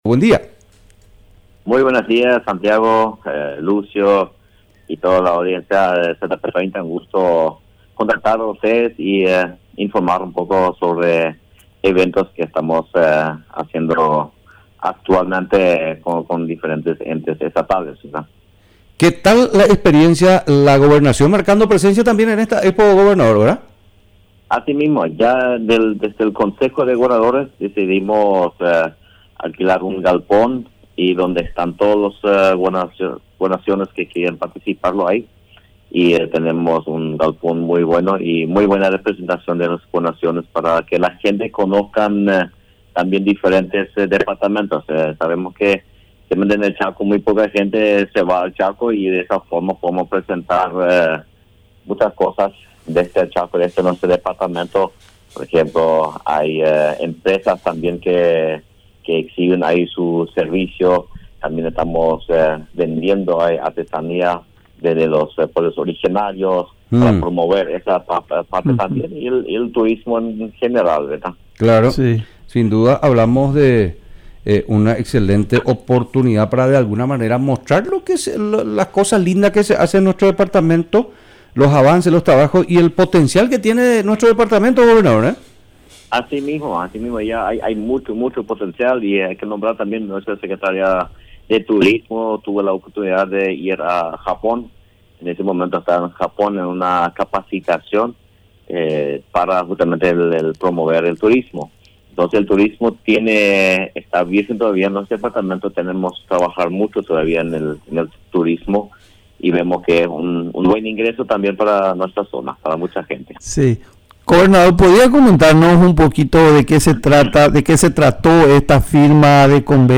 Entrevistas / Matinal 610 Crédito agrícola de habilitación Jul 18 2025 | 00:15:53 1x 00:00 / 00:15:53 Subscribe Share RSS Feed Share Link Embed